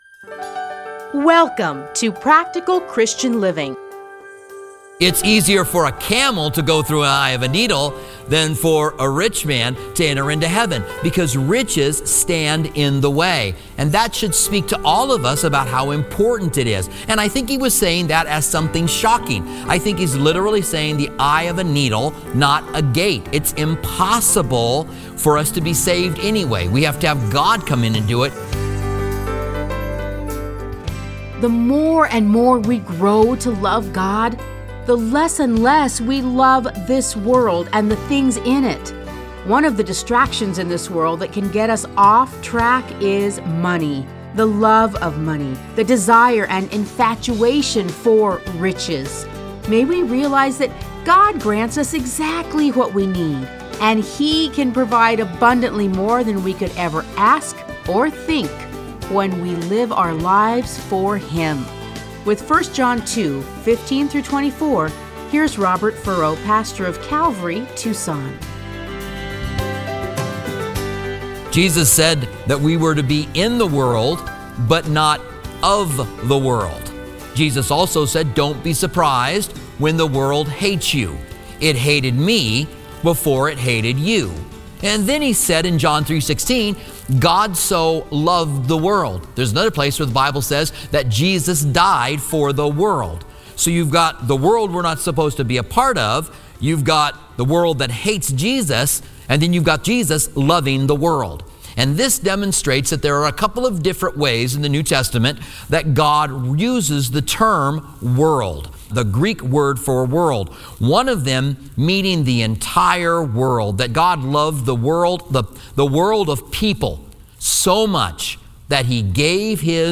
Listen to a teaching from 1 John 2:15-24.